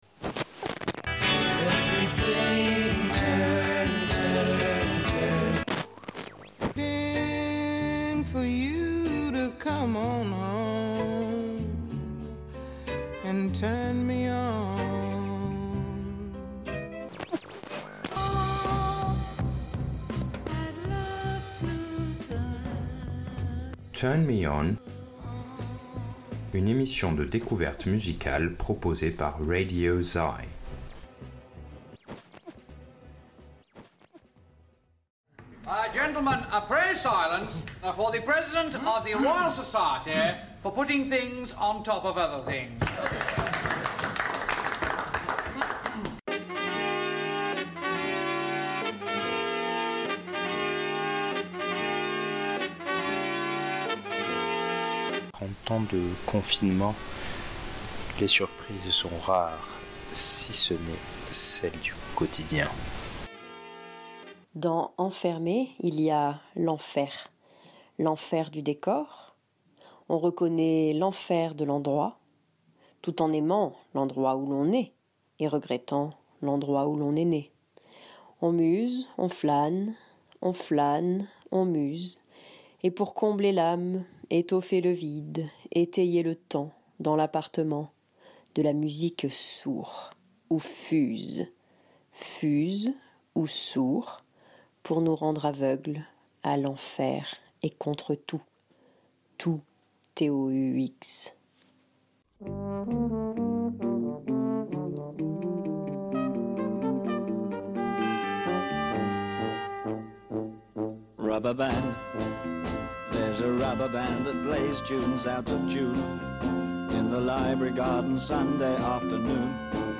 I asked people to send me a song they were listening to at the time.